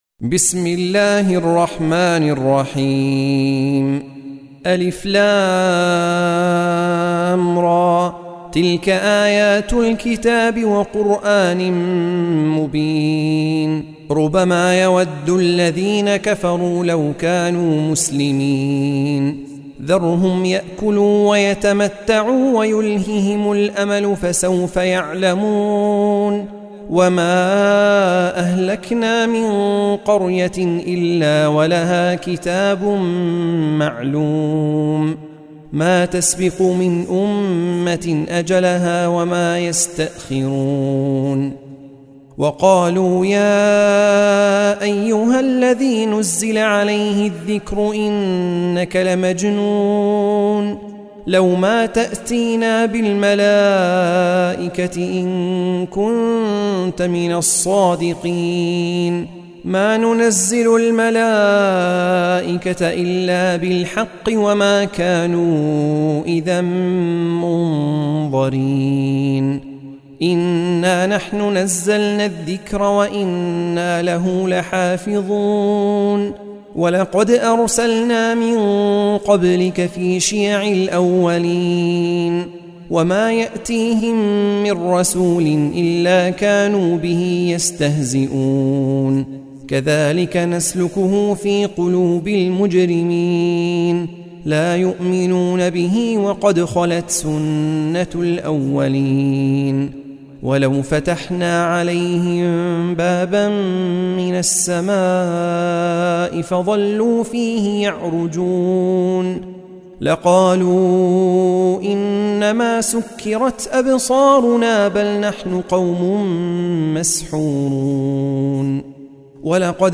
سورة الحجر | القارئ